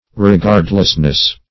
Re*gard"less*ness, n.